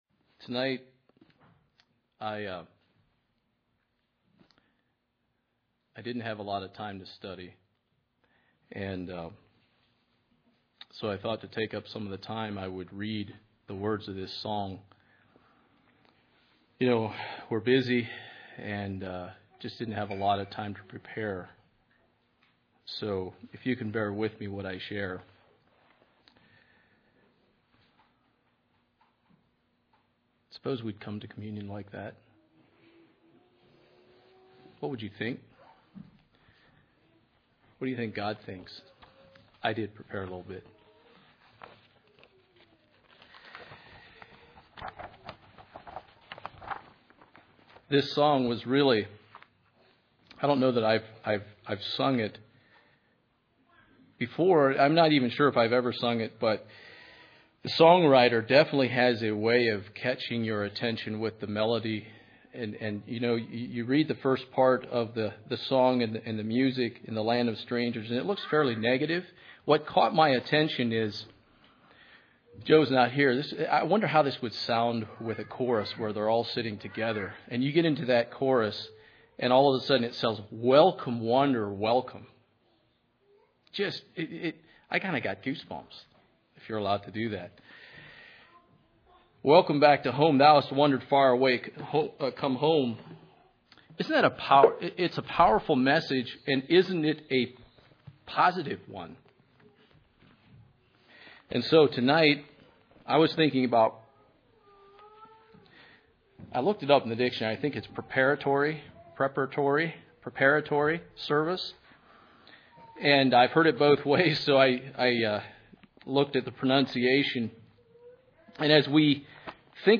Preparatory service message